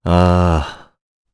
Mitra-vox-Sigh_jp.wav